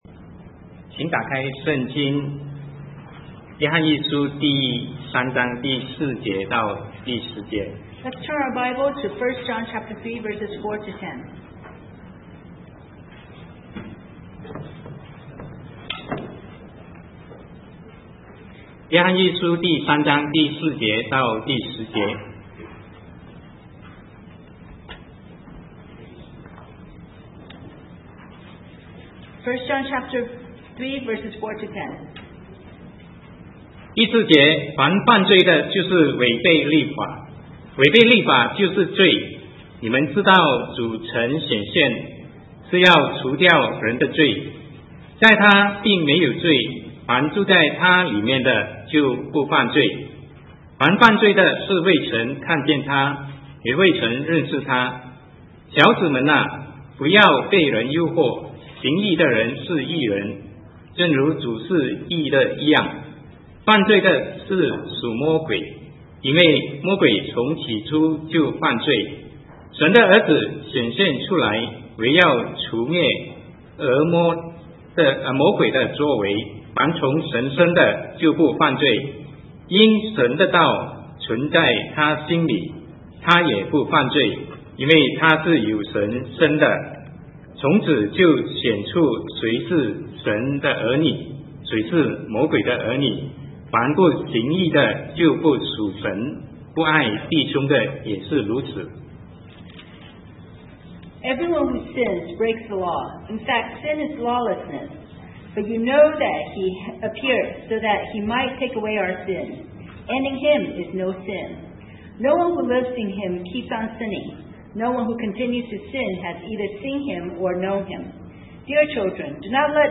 Sermon 2009-11-01 Do Not Sin Anymore